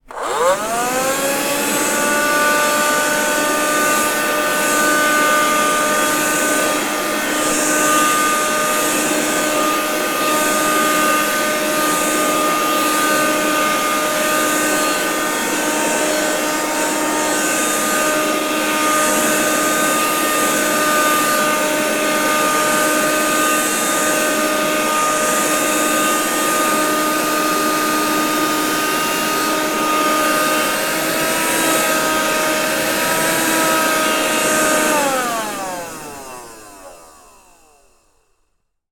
Звуки пылесоса
Вы можете слушать онлайн или скачать монотонный гул для маскировки шума, создания фона или использования в творческих проектах.
Dustbuster ручной пылесос для дома